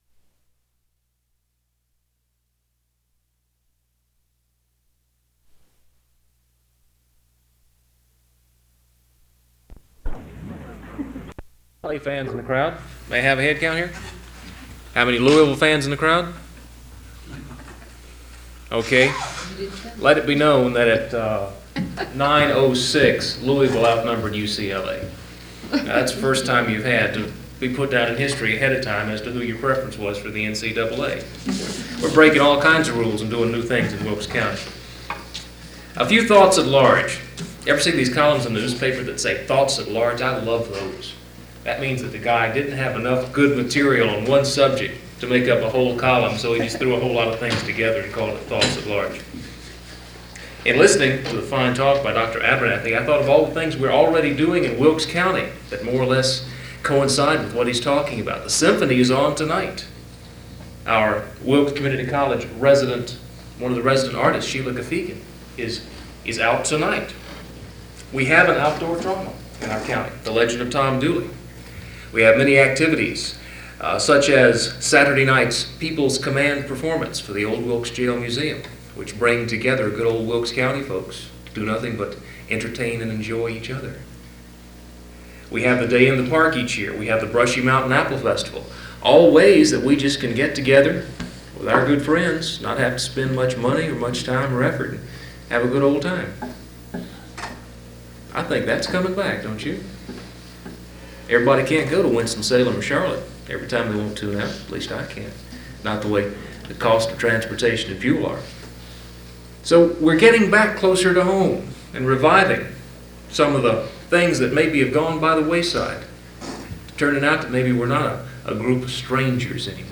A forum focused on the history of Wilkes County, including a lecture and a discussion.